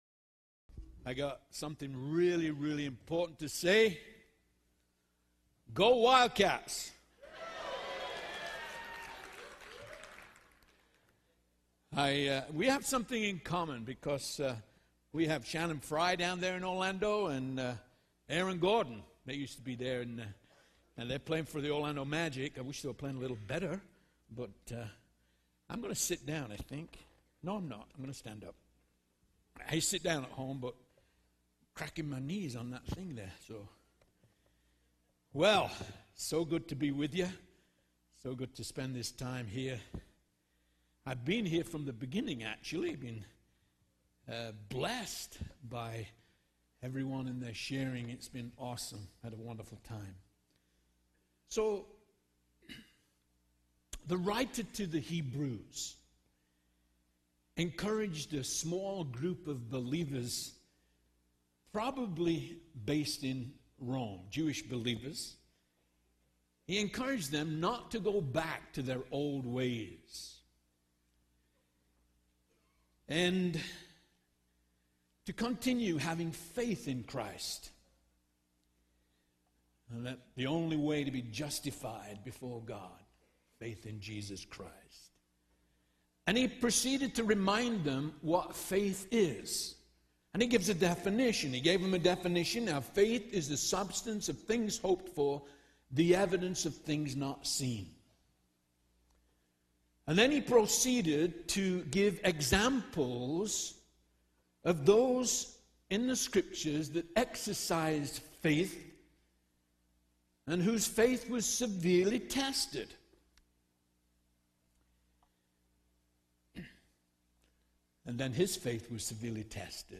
Southwest Pastors and Leaders Conference 2015